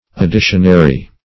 Additionary \Ad*di"tion*a*ry\, a.